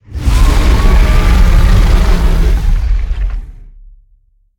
Sfx_creature_iceworm_roar_01.ogg